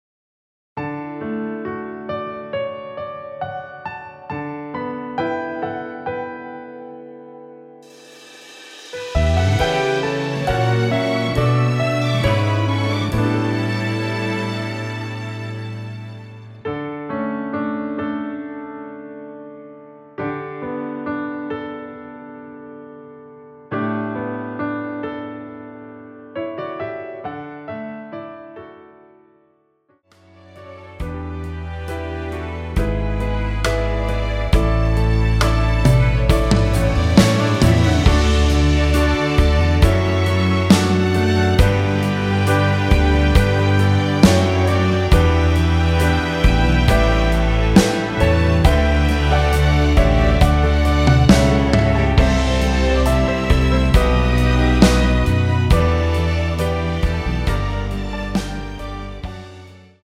원키에서(+3)올린 MR입니다.(미리듣기 참조)
앞부분30초, 뒷부분30초씩 편집해서 올려 드리고 있습니다.
중간에 음이 끈어지고 다시 나오는 이유는